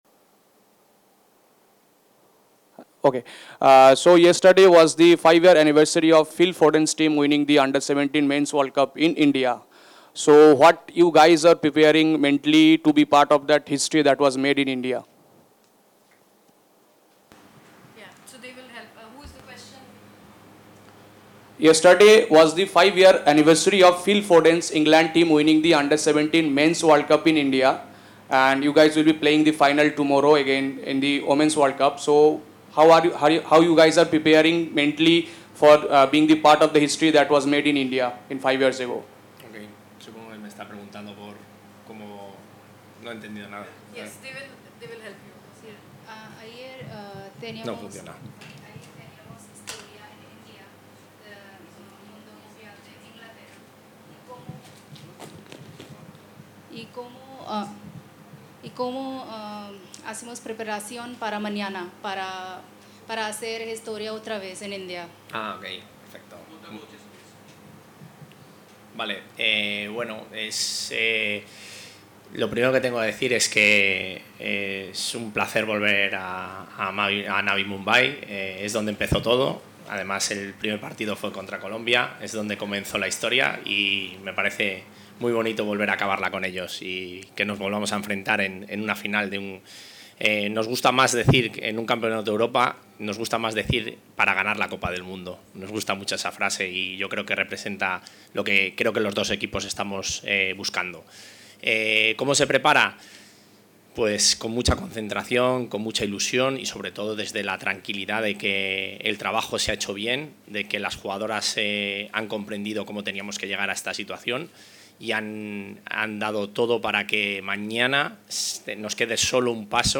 Conferencia-de-prensa-oficial.mp3